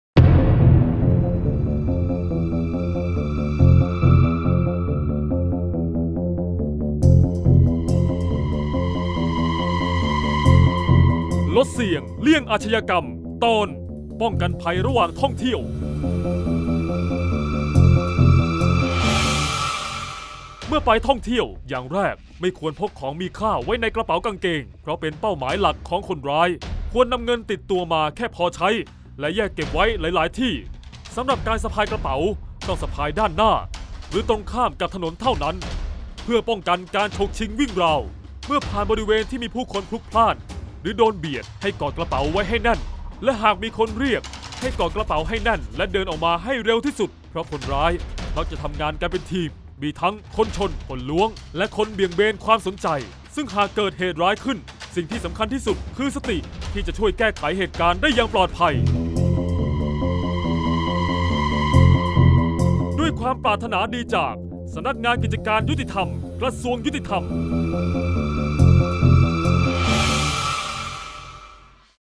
เสียงบรรยาย ลดเสี่ยงเลี่ยงอาชญากรรม 27-ป้องกันภัยเมื่อท่องเที่ยว